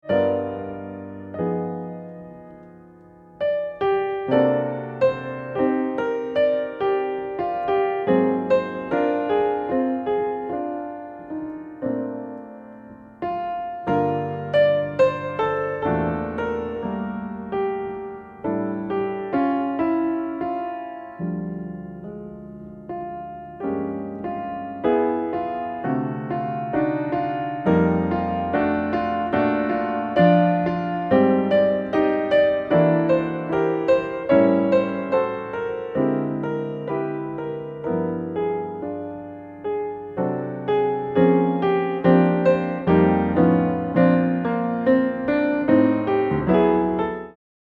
piano introduction